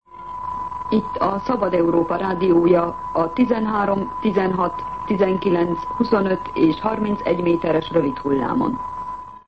Frekvenciaismertetés